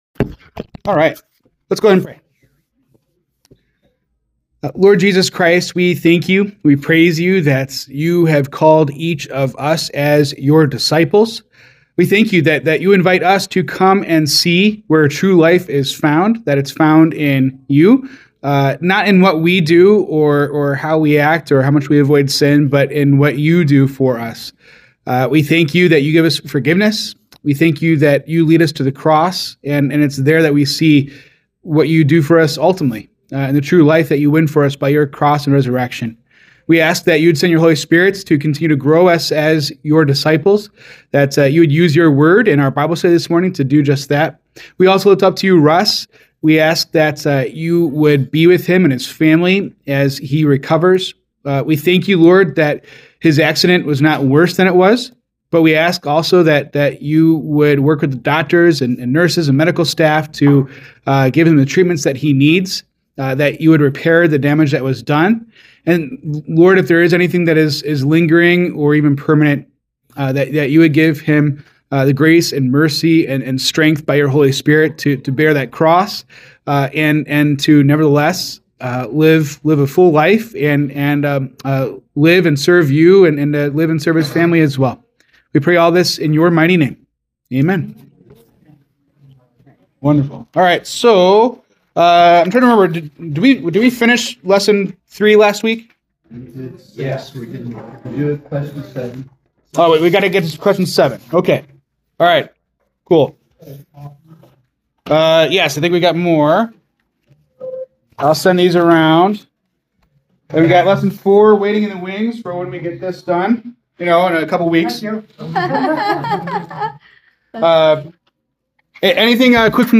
January 18, 2026 Bible Study
Discussion on John not knowing Jesus and familiarity vs revelation followed by Revelation 5.